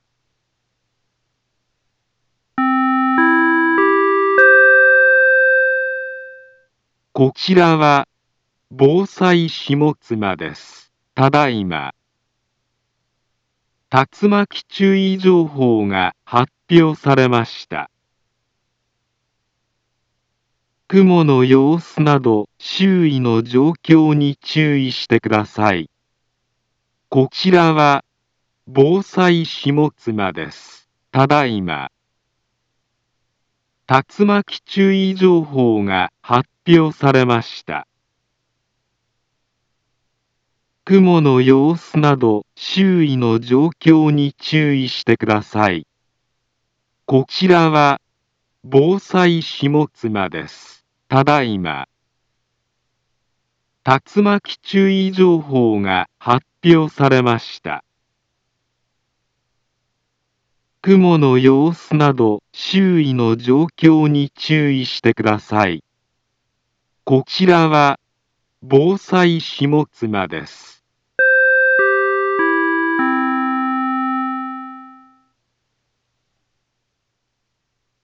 Back Home Ｊアラート情報 音声放送 再生 災害情報 カテゴリ：J-ALERT 登録日時：2023-07-12 15:45:11 インフォメーション：茨城県南部は、竜巻などの激しい突風が発生しやすい気象状況になっています。